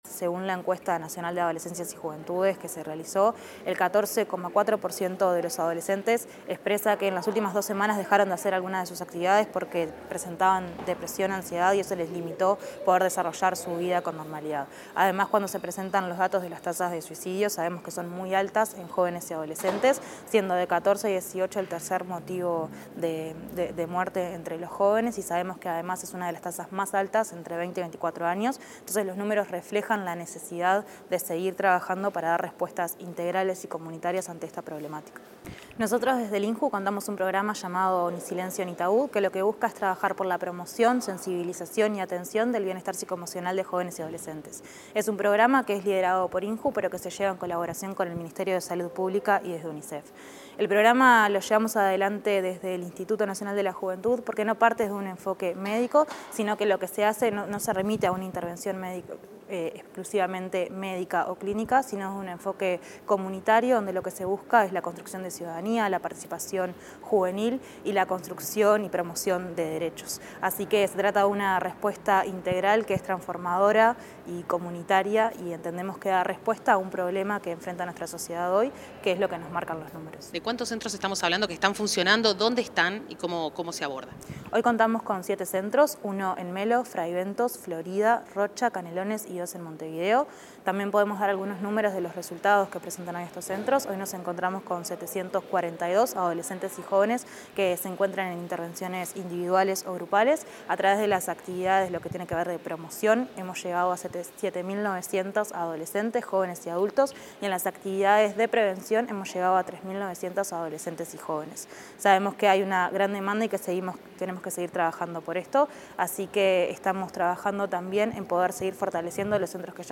Declaraciones de la directora del INJU, Eugenia Godoy